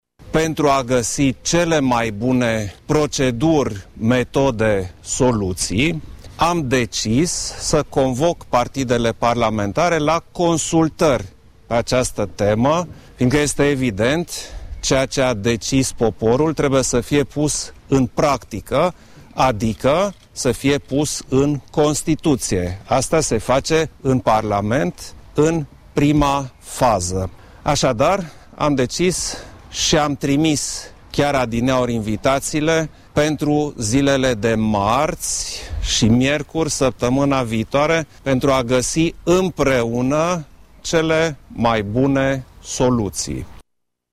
Trebuie să decidem cum să punem în practică ce a decis poporul, a subliniat preşedintele Klaus Iohannis:
Iohannis-consultari.mp3